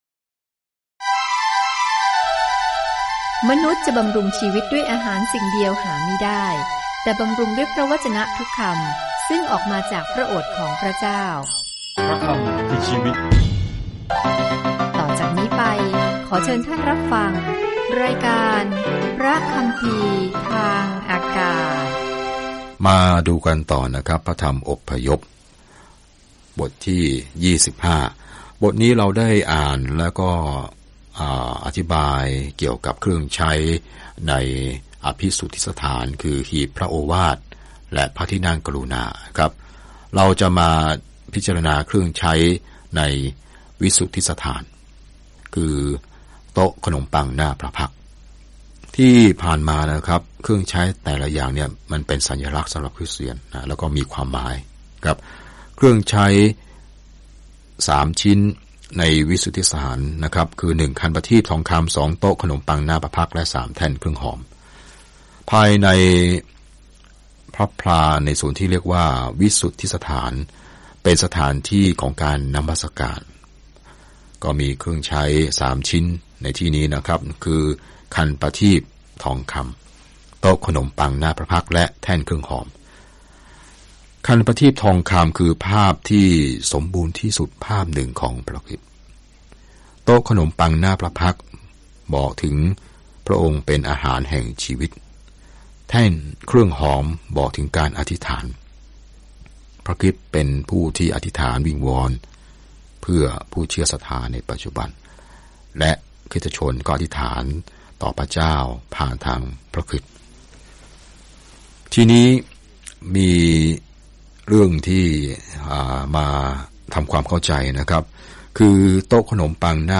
ในขณะที่คุณฟังการศึกษาด้วยเสียงและอ่านข้อที่เลือกจากพระวจนะของพระเจ้า